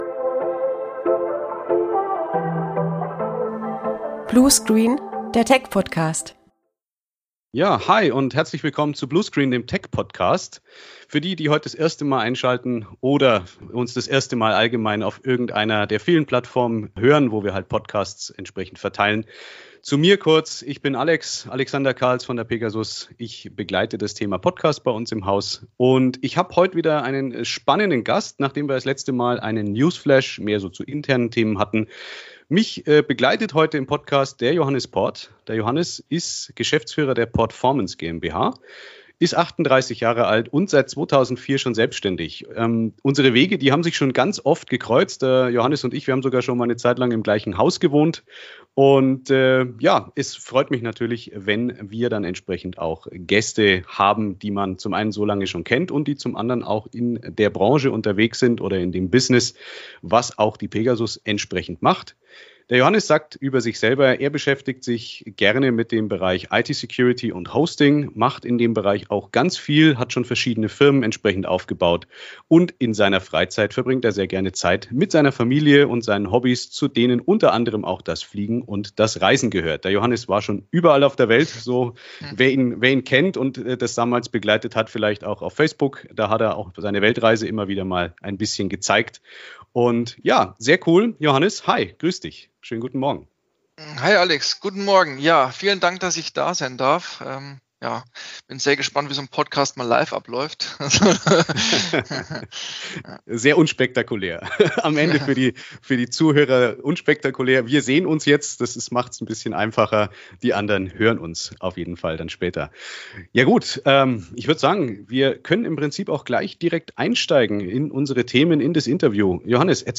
Folge 4 von Bluescreen - Der Tech-Podcast! Im Interview